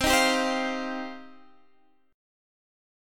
C Minor Add 11th